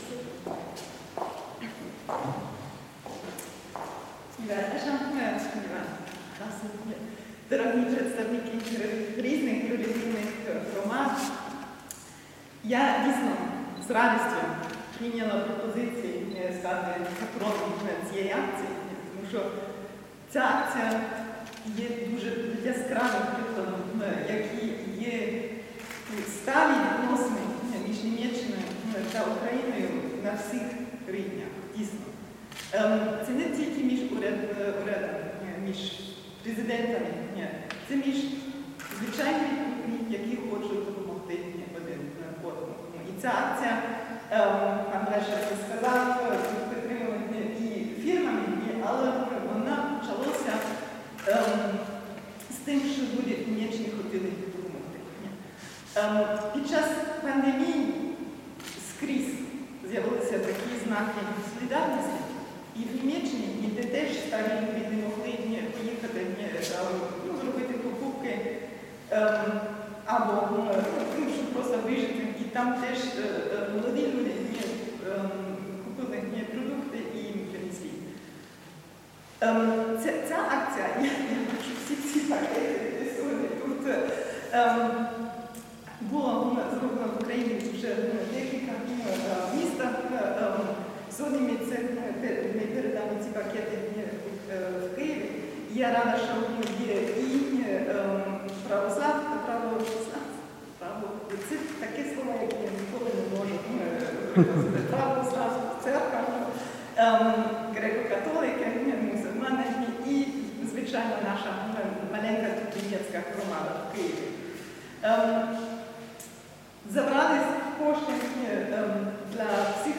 Rede zum Nachhören – nur ukrainisch)
Rede_Feldhusen.mp3